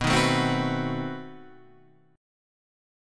B7b9 chord {x 2 4 5 4 5} chord